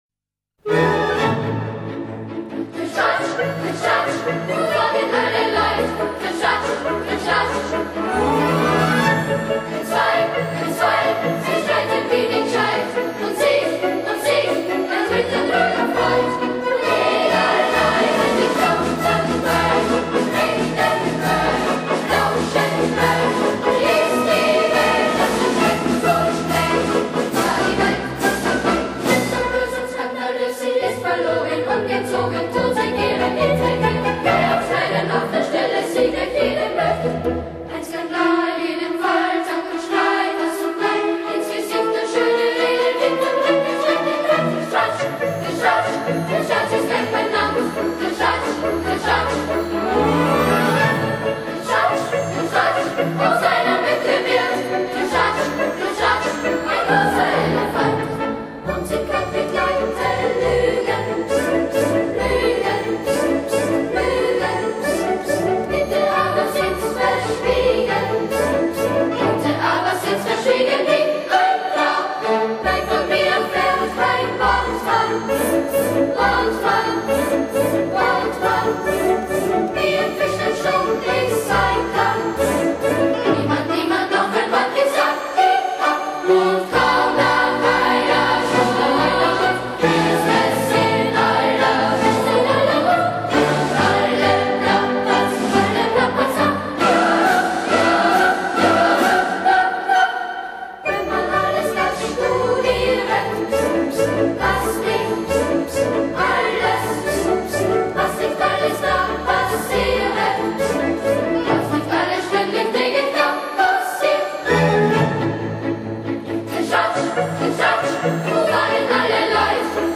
由11-14岁男童组成
由这群声若银铃的小天使唱来，不仅保存了原有的华彩，更添几许清新。